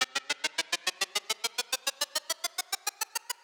FX